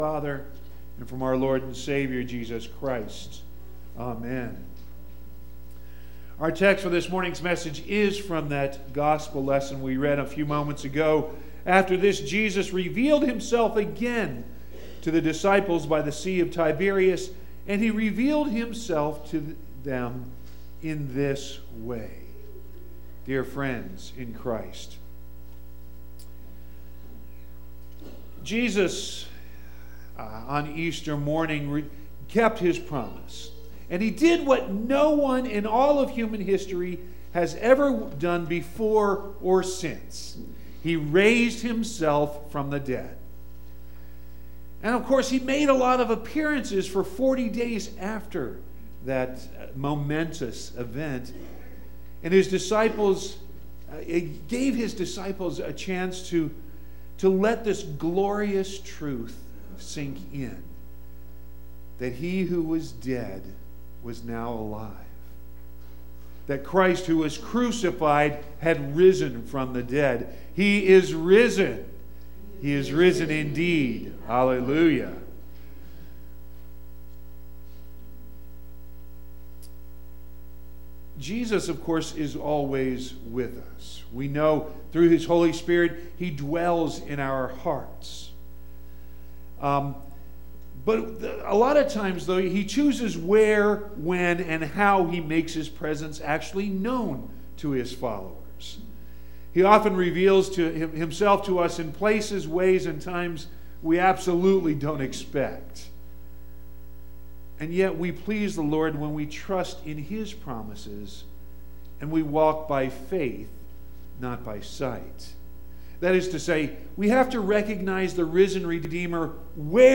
5.5.19-sermon.mp3